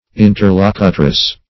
Interlocutrice \In`ter*loc"u*trice\, n.